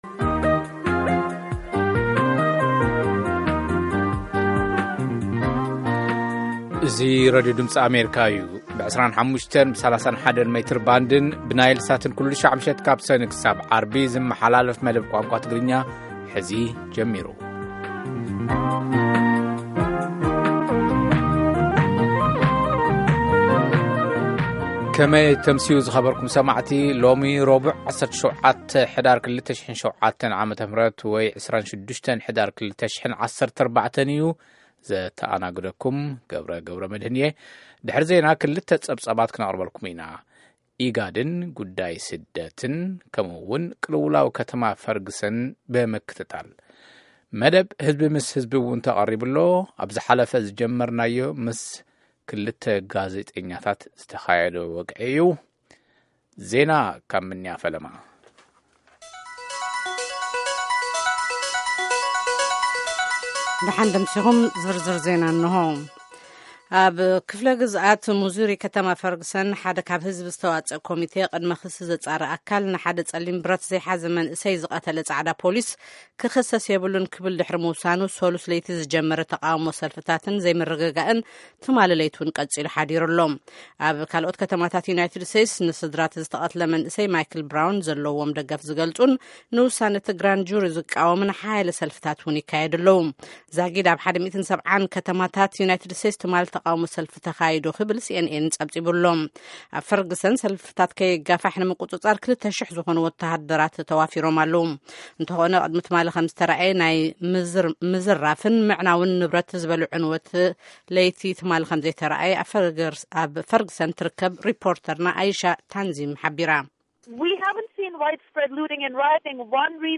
Tigrigna News 1900